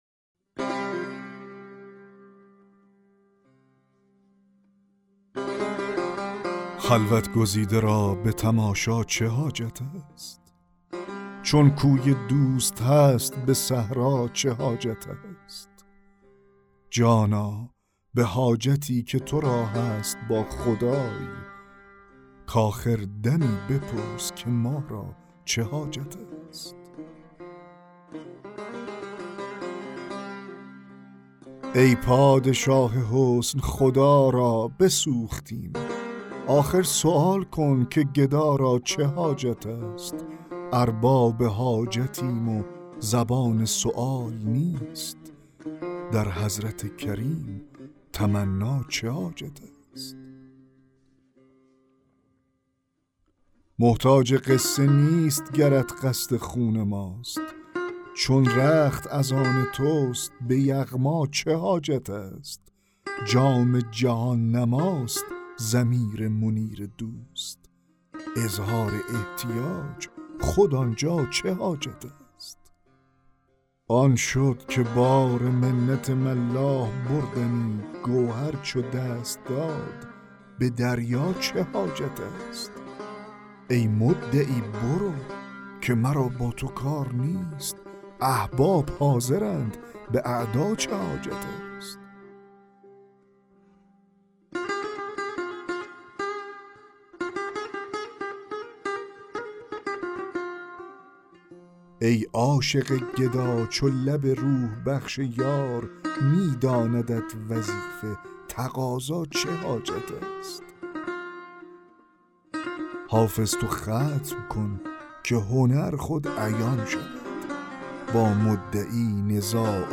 دکلمه غزل 33 حافظ